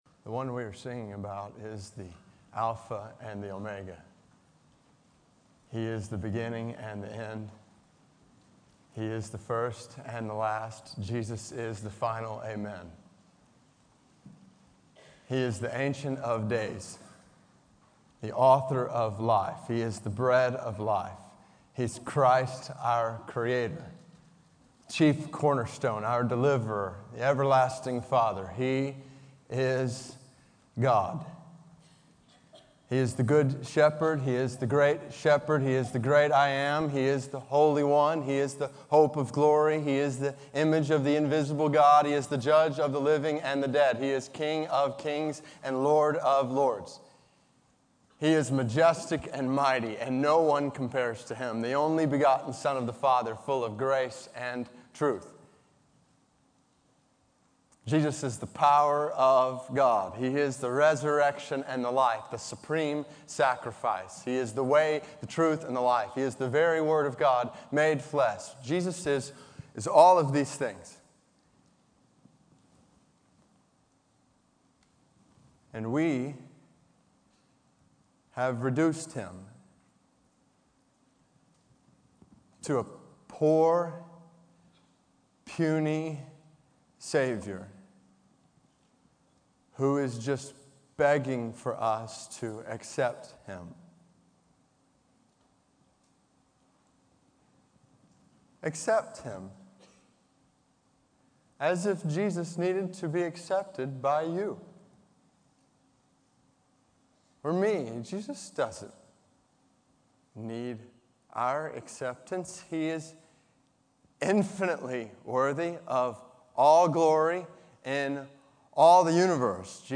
Faith in Practice Chapel: David Platt